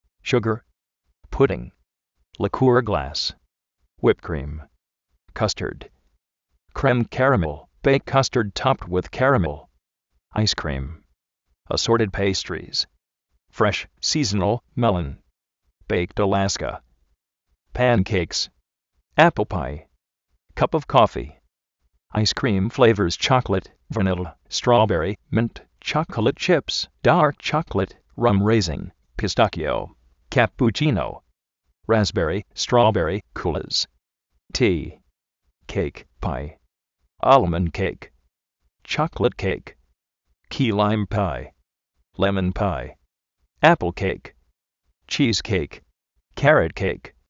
shúger
uípt krím
kástard
áis krim
ápl pái
chí:s-kéik